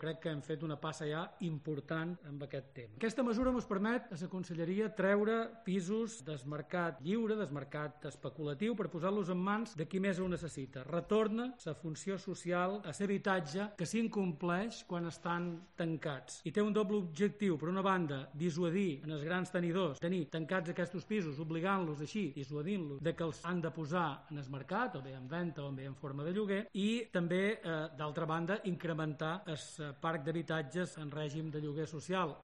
El conseller de movilidad y vivienda, Josep Marí.